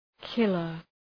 Προφορά
{‘kılər}